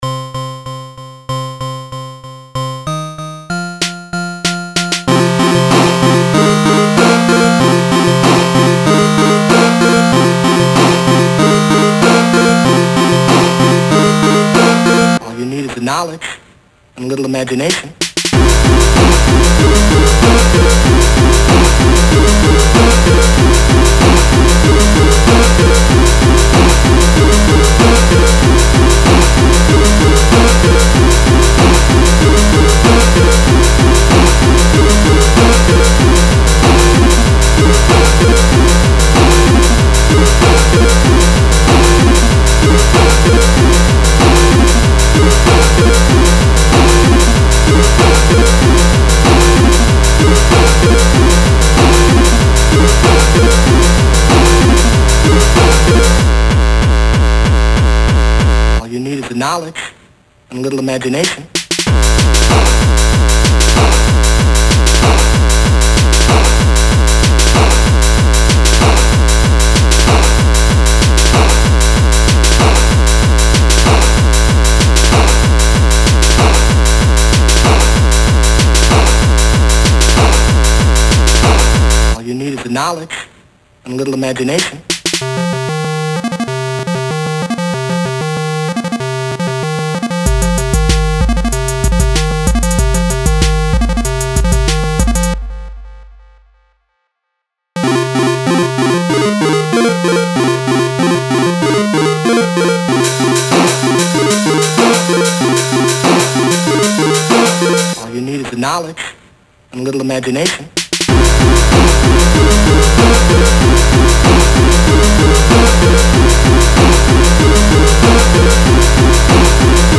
Another song from my album, i was trying out chiptune for the first time in this one. hope you like it!
Thanks for Reading! 91 Views 0 Favorites 0 Comments General Rating Category Music / Techno Species Unspecified / Any Gender Any Size 50 x 50px File Size 3.66 MB Keywords chiptune techno gabber hardcore rave breakcore breakbeat mashcore Listed in Folders Music!